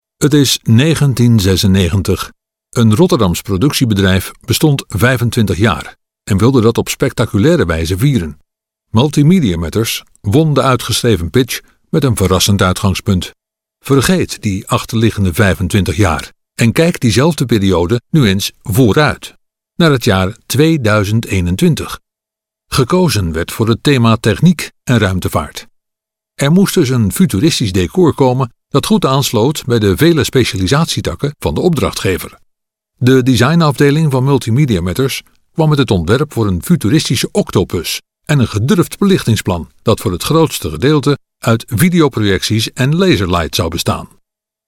NL RB EL 01 eLearning/Training Male Dutch